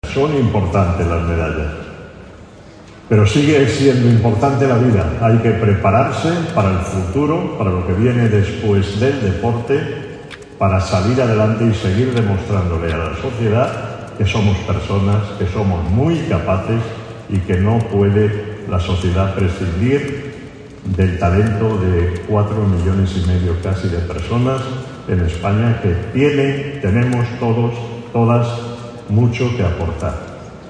Foto de familia del acto de acogida en Madrid del equipo paralímpicoLa ministra de Educación, Formación Profesional y Deportes, Pilar Alegría, junto al ministro de Derechos Sociales, Consumo y Agenda 2030, Pablo Bustinduy, homenajearon el 10 de seprtiembre en Madrid al Equipo Paralímpico Español, tras el éxito logrado en los Juegos de París 2024 en los que ha logrado un total de 40 medallas.